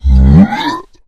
spawners_mobs_balrog_attack.1.ogg